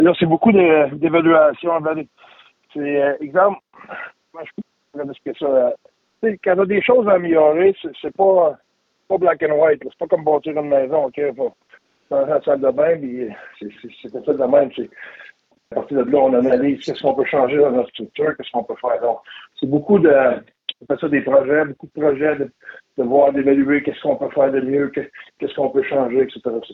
L’entraîneur-chef de la formation de l’Utah a résumé ce qu’il a fait depuis la fin de la campagne.